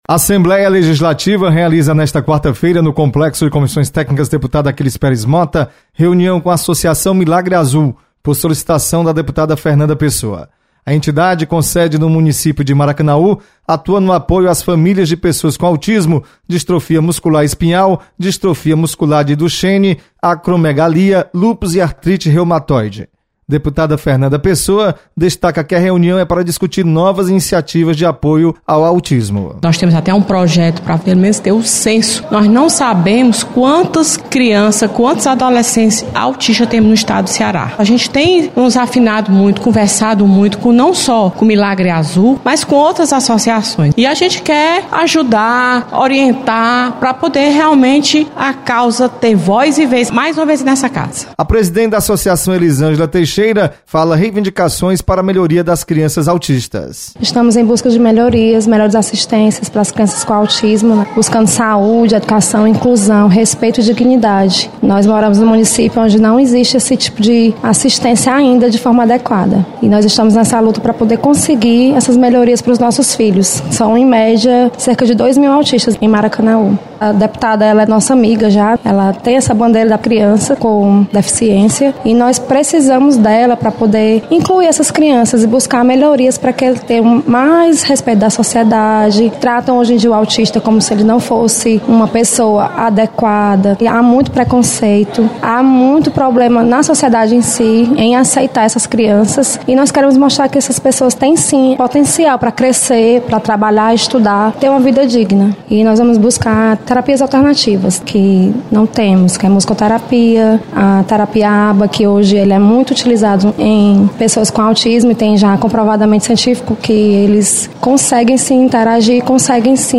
Assembleia realiza reunião com entidade sobre cuidado com as pessoas autistas. Repórter